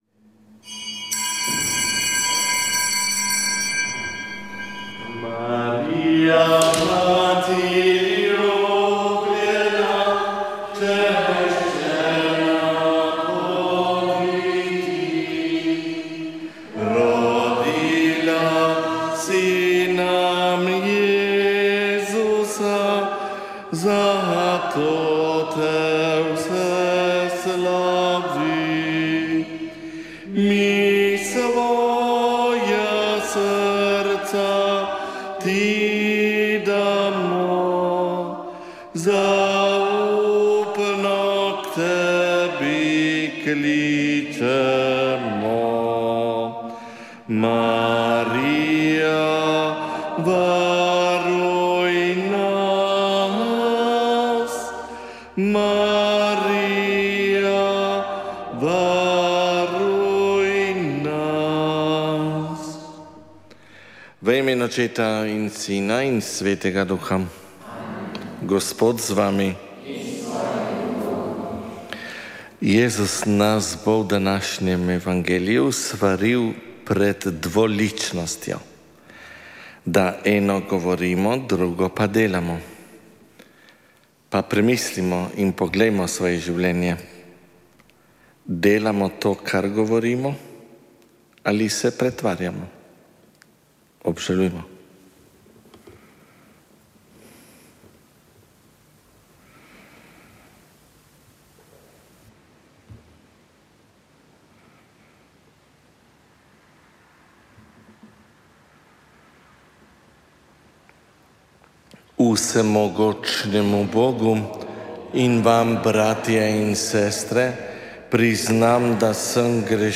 Sv. maša iz cerkve svetega Mihaela Grosuplje 24. 8.
Pri maši je sodeloval otroški pevski zbor